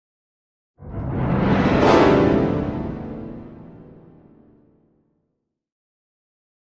Piano Fast Ascending Tension - Hard Gliss